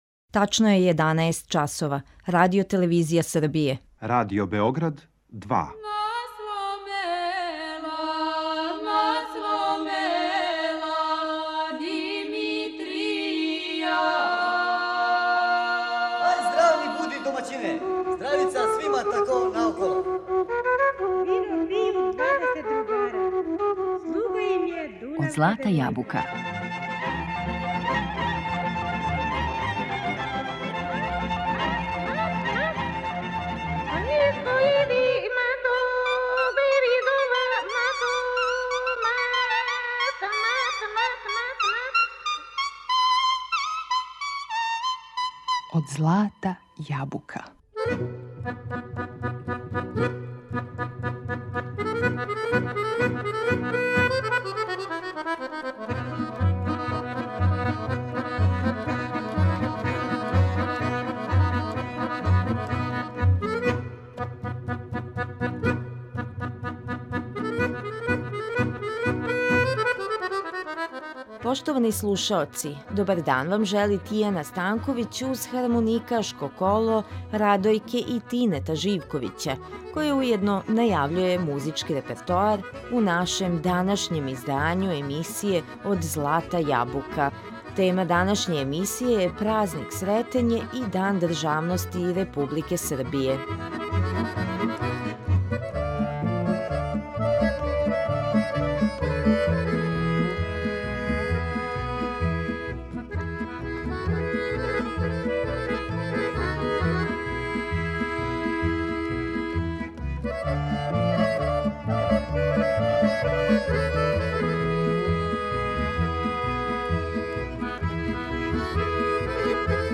На репертоару су снимци хармоникашких кола у извођењу наших признатих виртуоза на овом инструменту.